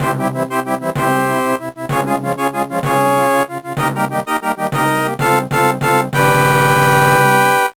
The faster arrangement